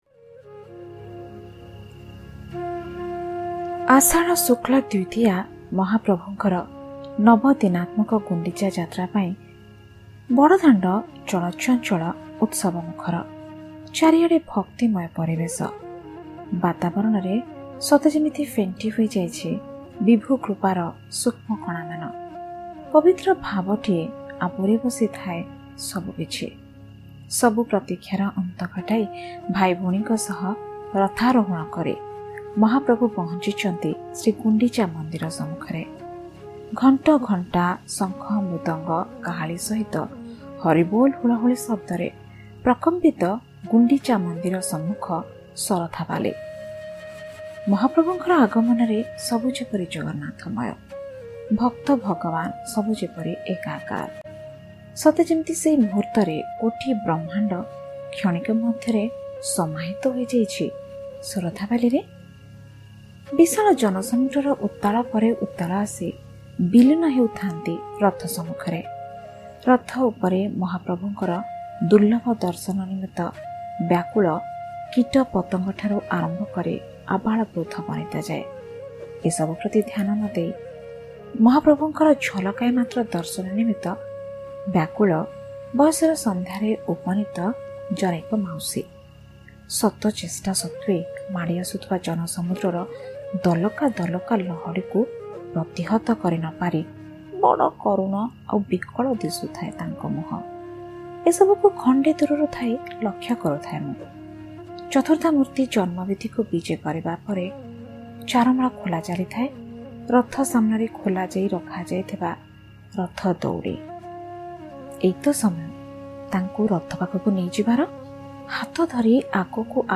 Odia Poem